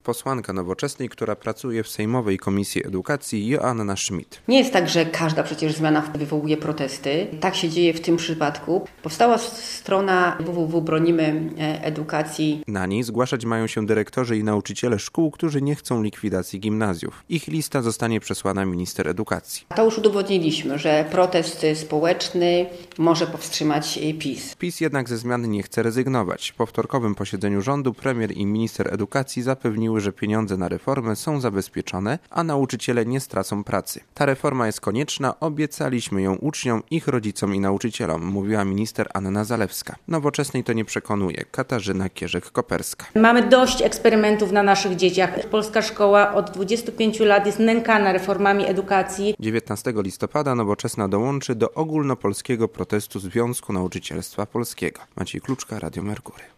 Poniżej relacja